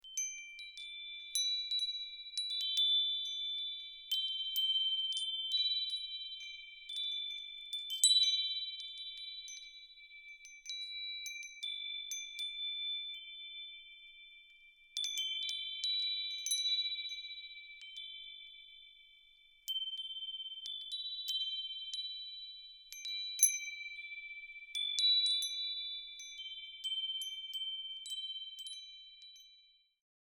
Carillon à Vent Maori | Nouvelle Zélande
• Grâce à la girouette en forme de masque traditionnel, ou koruru, vous serez bercé par la mélodie Pokare Kare Ana chaque fois que le vent soufflera
• 6 tubes en aluminium de couleur bronze